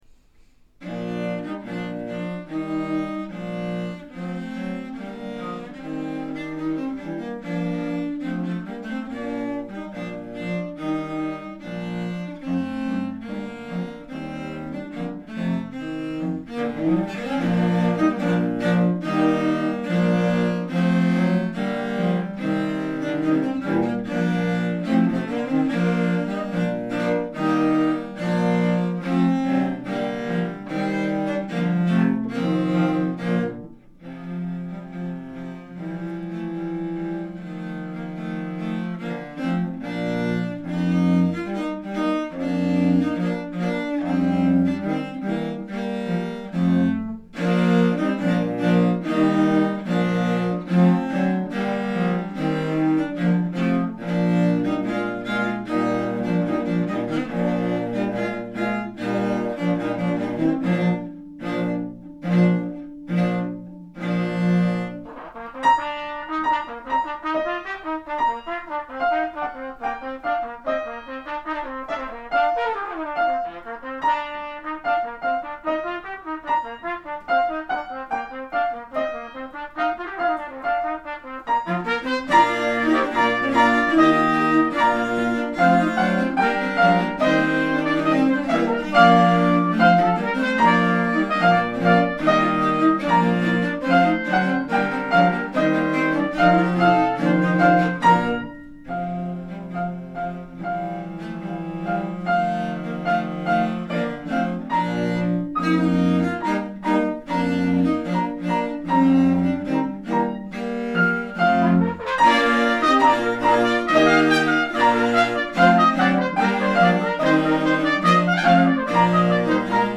Optionally substitute alternative pitched percussion or piano (8va, as in the sample recording) for chimes.
St.-Anthony-Chorale-Cellos-Tpt.mp3